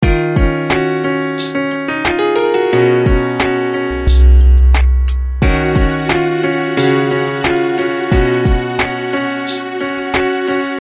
心脏跳动的原始Grooveloop
Tag: 89 bpm Hip Hop Loops Groove Loops 1.81 MB wav Key : Unknown